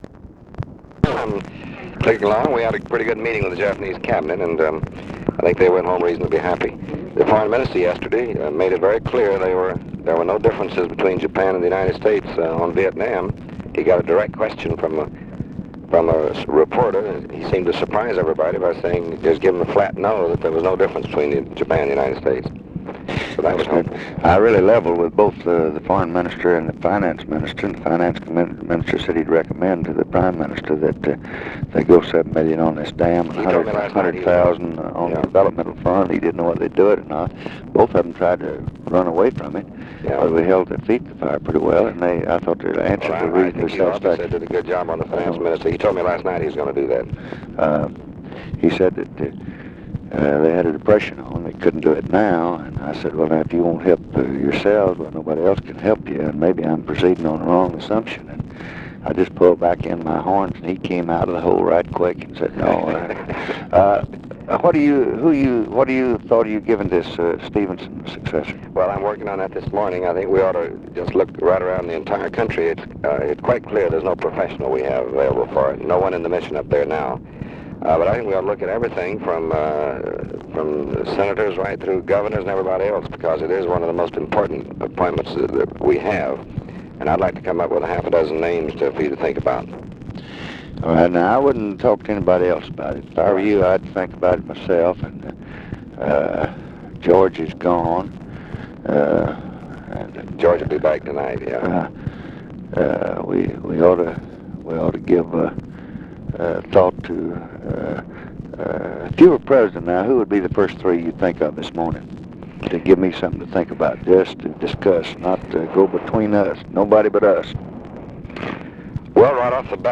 Conversation with DEAN RUSK, July 15, 1965
Secret White House Tapes